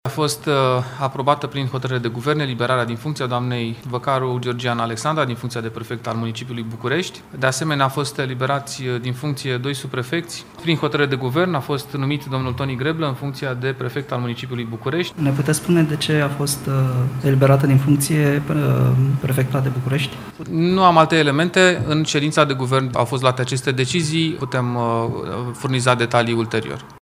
Purtatorul de cuvânt al Guvernului, Dan Cărbunaru: